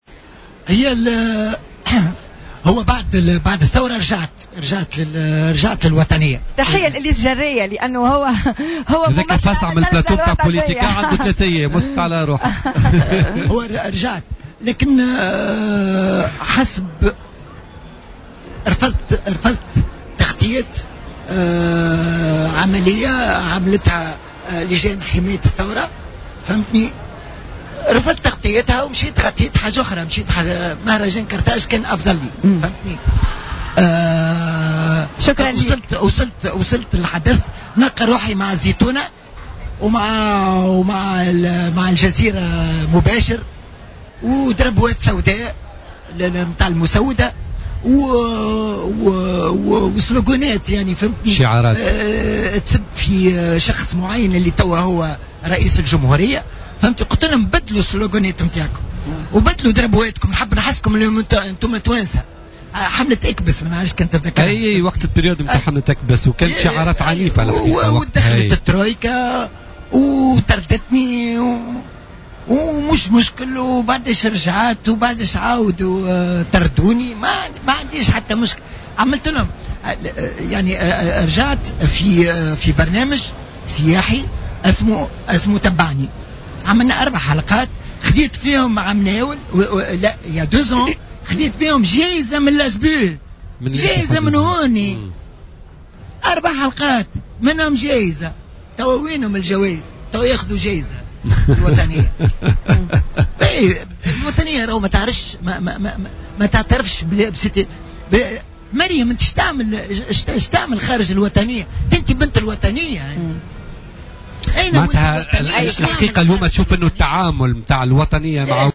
على هامش المهرجان العربي للإذاعة والتلفزيون في الحمامات